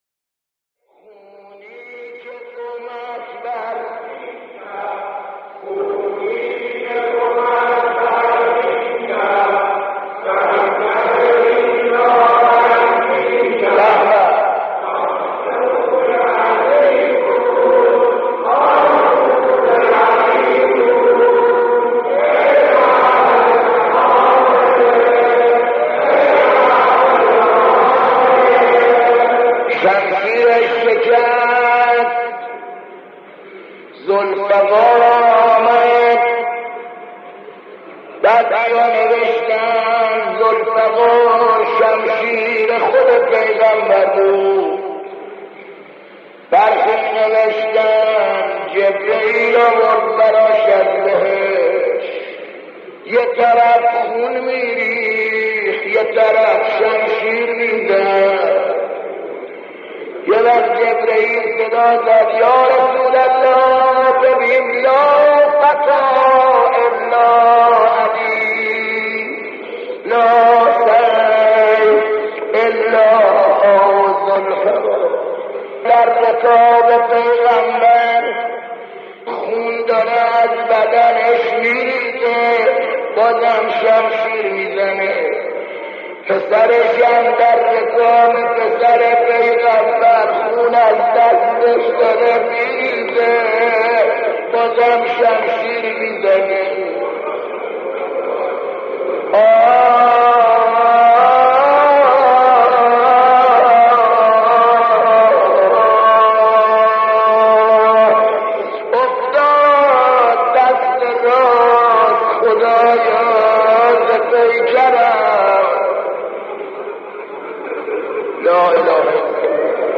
در پرده عشاق، صدای مداحان و مرثیه‌خوانان گذشته تهران قدیم را خواهید شنید که صدا و نفسشان شایسته ارتباط دادن مُحب و مَحبوب بوده است.